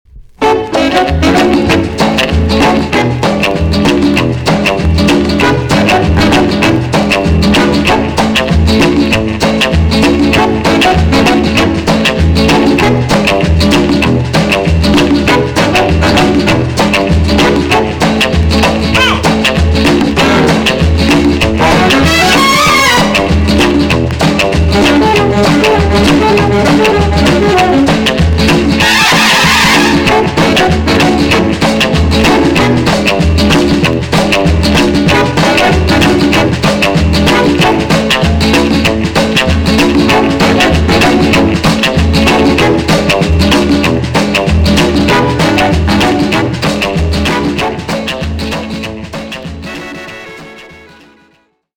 EX- 音はキレイです。
1958 , WICKED MAMBO JAZZ TUNE!!